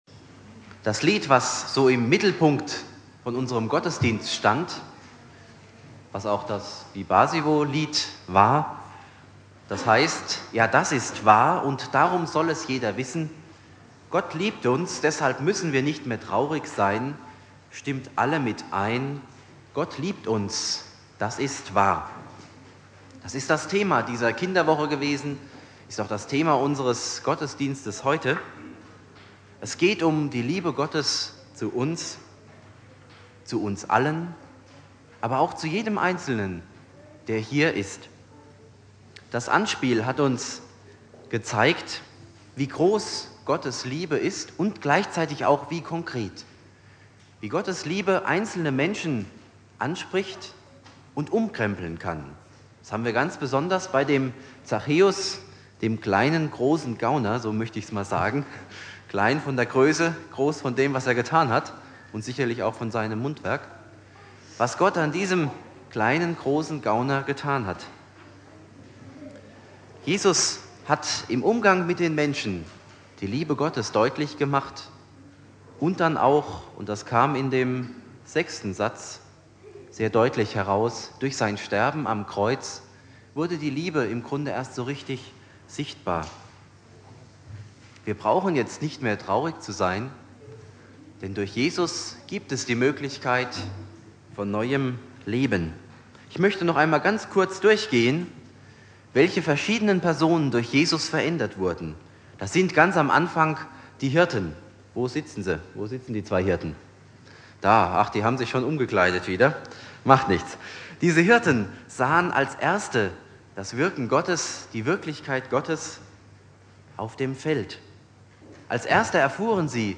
Predigt
Familiengottesdienst zum Ende der Kinderwoche